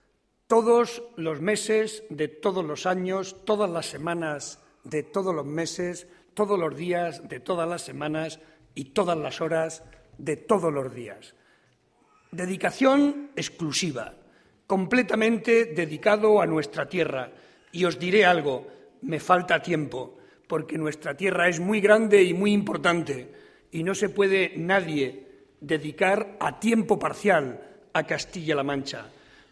En la quinta jornada de campaña Barreda centró los mítines en la provincia de Toledo